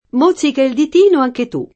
mozzico [ m 1ZZ iko ]